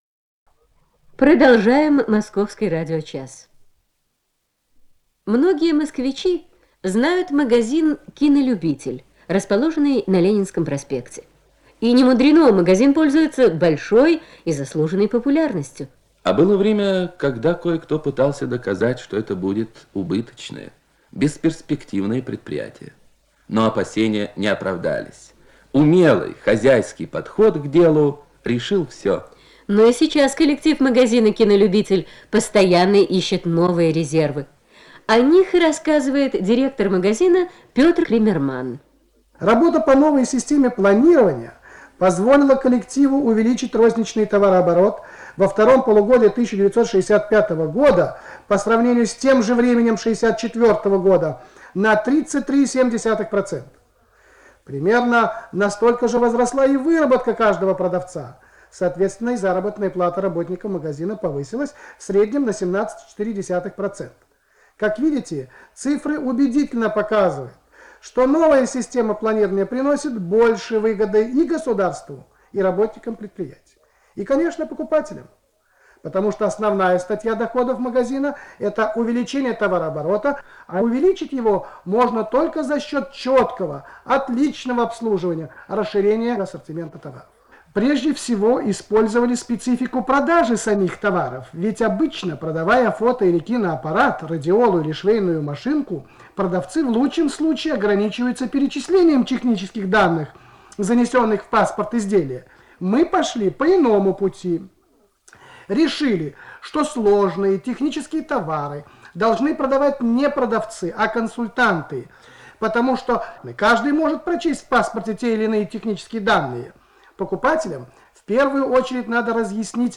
с профессиональной магнитной ленты
Скорость ленты19 см/с
ВариантМоно
МагнитофонМЭЗ-109А